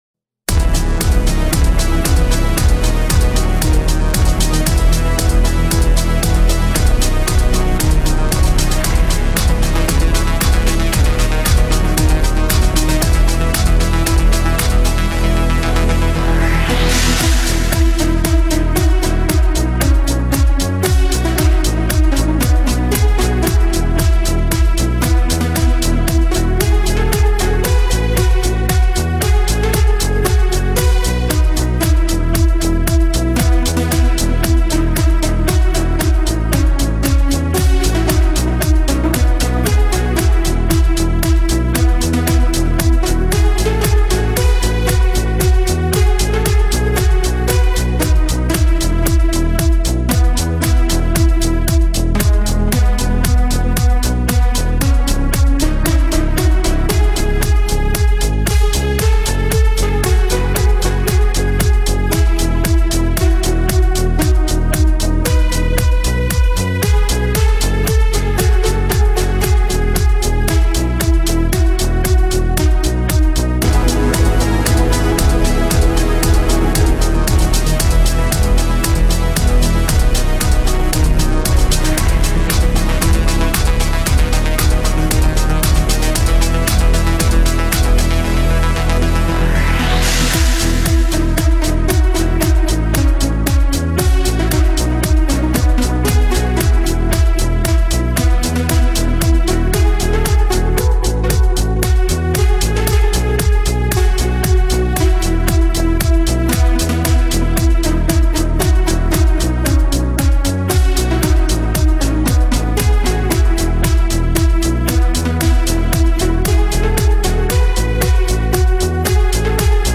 REMİX E MAJOR KARAOKE